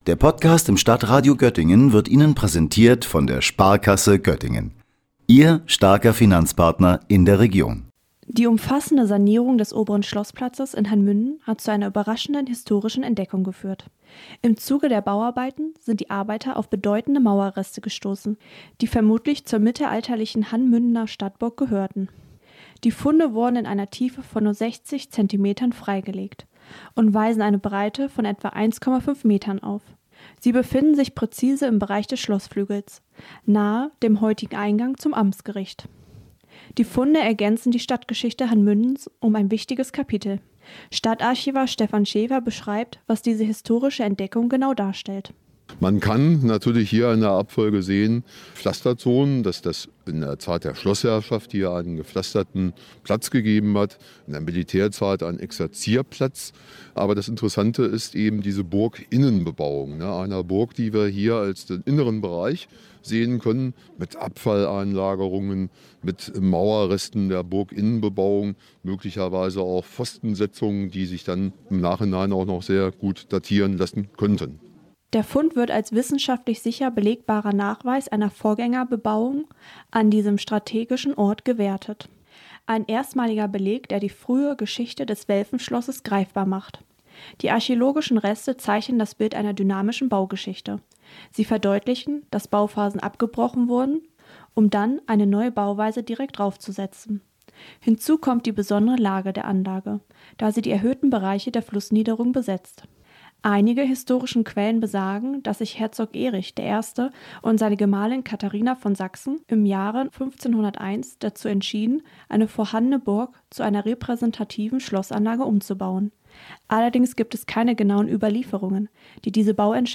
Beiträge > Alte Fundamentteile in Hann.